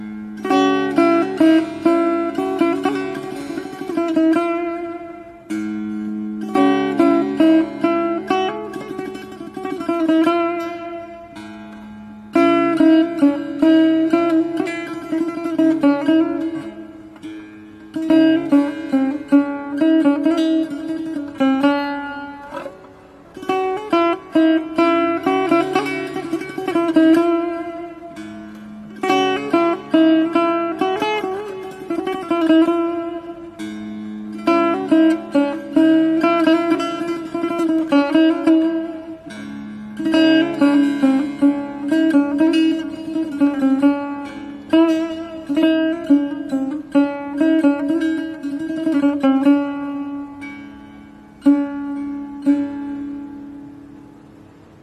صدای زنگ سنتنی شیک